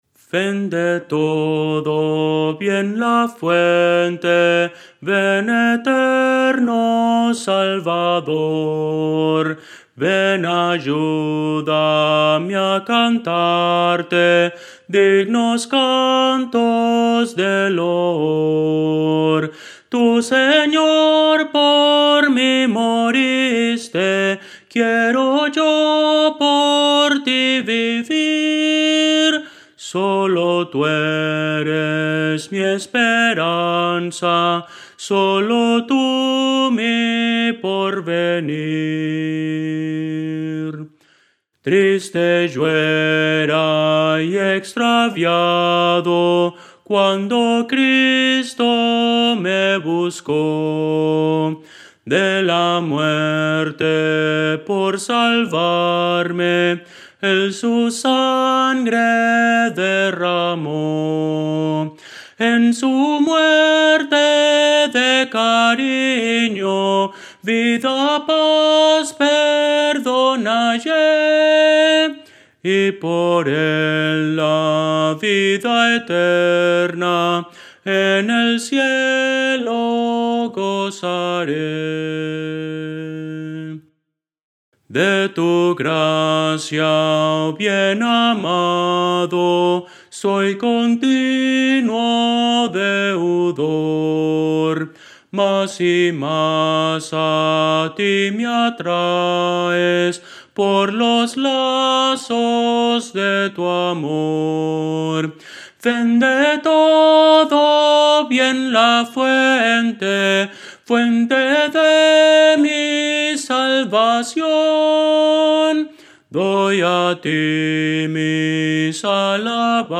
Voces para coro
Soprano – Descargar